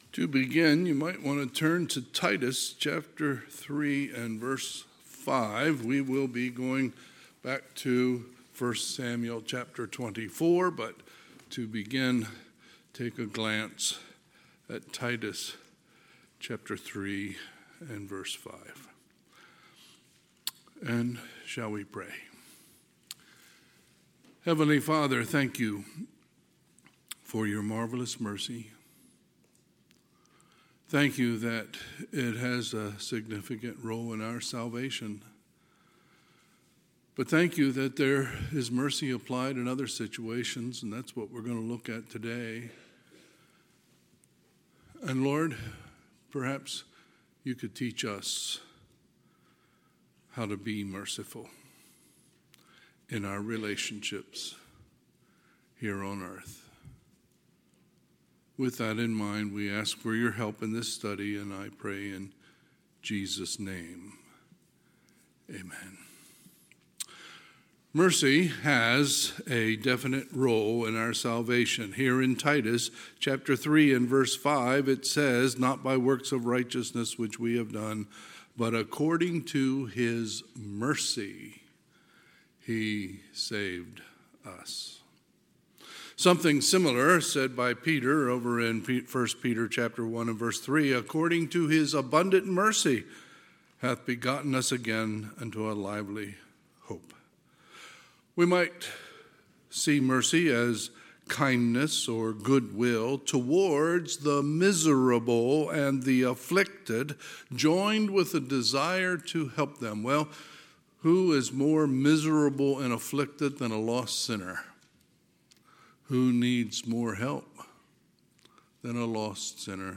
Sunday, March 23, 2025 – Sunday AM
Sermons